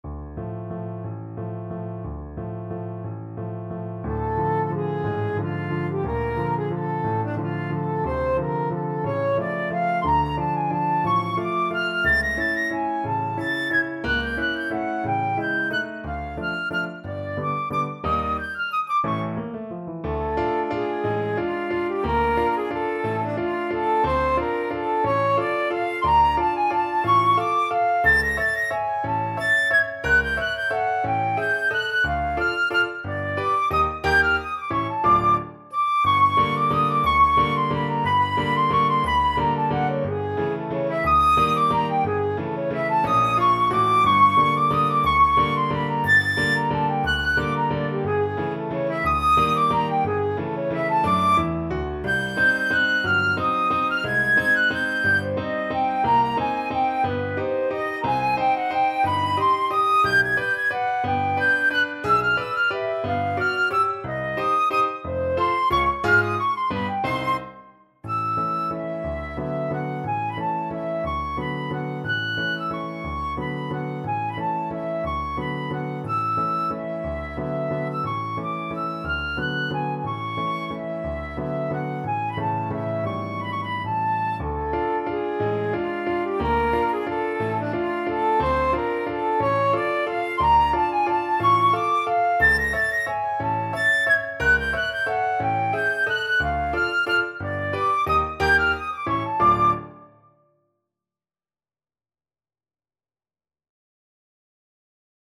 Flute version
3/4 (View more 3/4 Music)
Allegro espressivo .=60 (View more music marked Allegro)
Classical (View more Classical Flute Music)